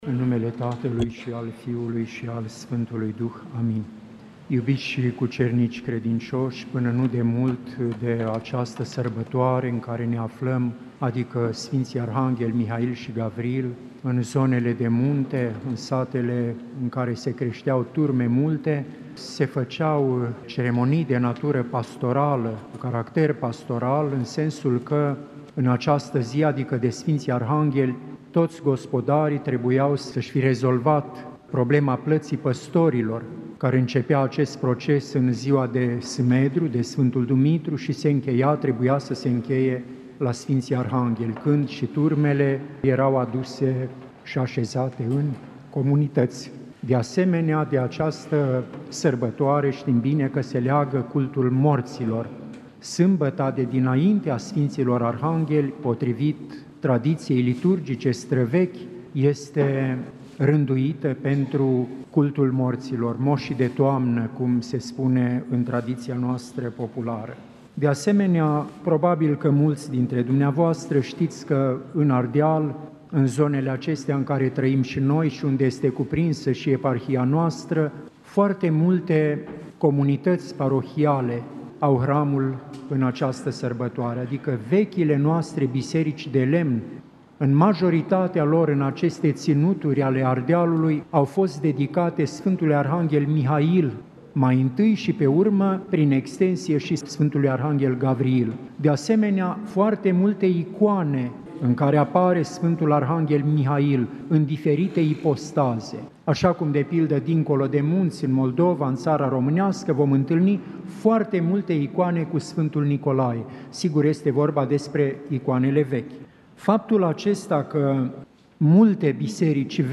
Predică la sărbătoarea Sfinților Arhangheli Mihail și Gavriil